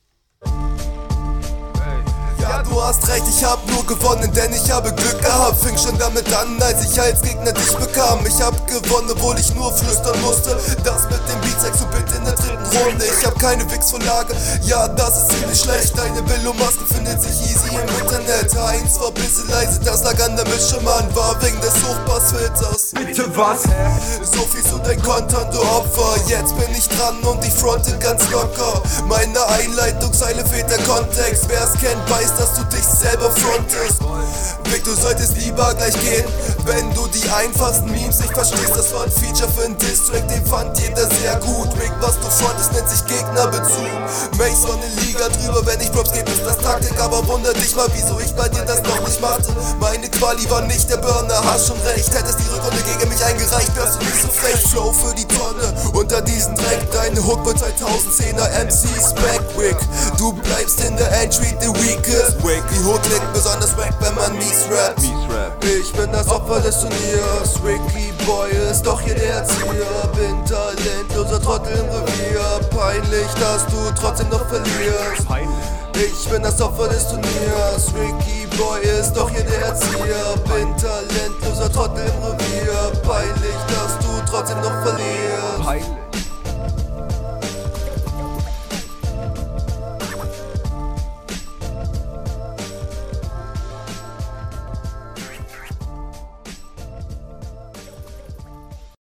Soundtechnisch leider auch nicht grade nice.
klingt schon cooler als deine hr1. text ist diesmal sogar was. für mich ne kleine …